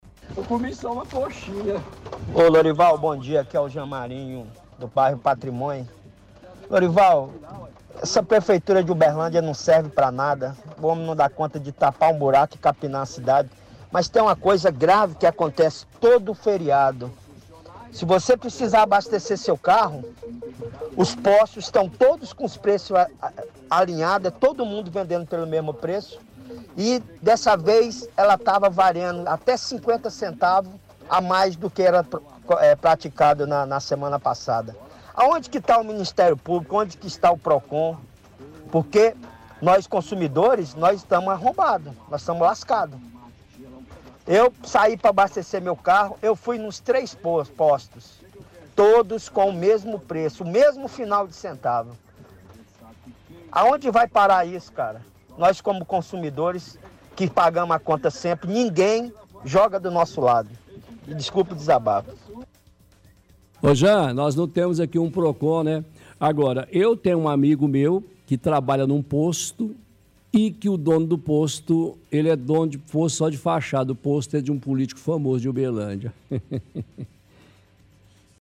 – Ouvinte fala que a prefeitura não presta pra nada, fala que a cidade está cheia de buraco e matos altos. Reclama que no feriado foi abastecer o seu carro, porém os preços estão tudo caro.